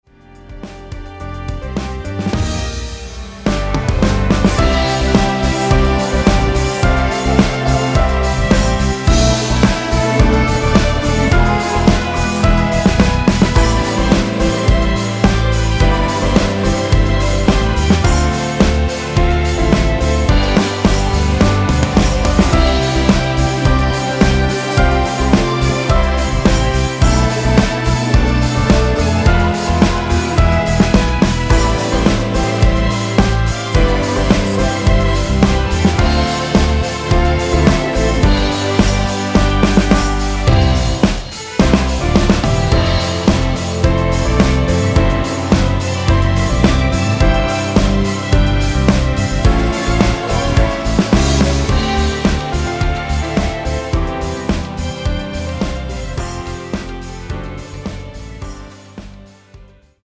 전주 없이 시작 하는곡이라 노래 하시기 좋게 2마디 전주 만들어 놓았습니다.(약 5초쯤 노래 시작)
Bb
◈ 곡명 옆 (-1)은 반음 내림, (+1)은 반음 올림 입니다.
앞부분30초, 뒷부분30초씩 편집해서 올려 드리고 있습니다.